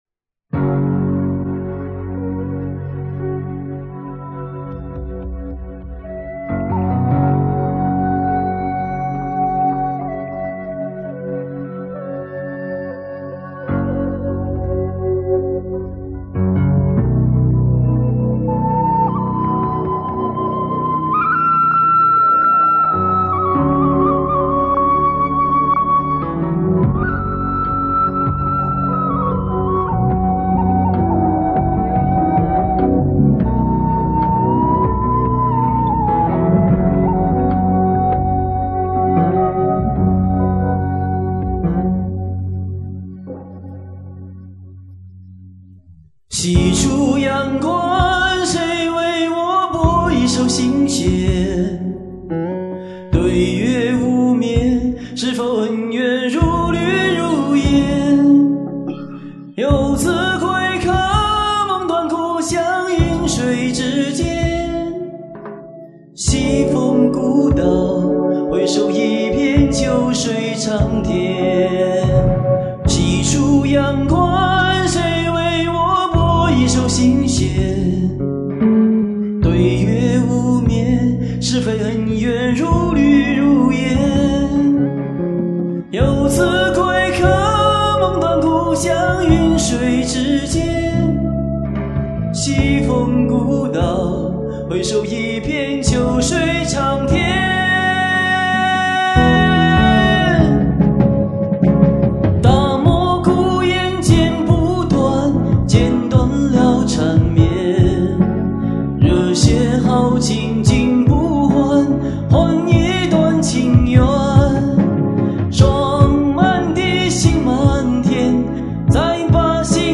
同样一种澎湃的豪情，同样一种磅礴的气势，一瞬间就攫住了我。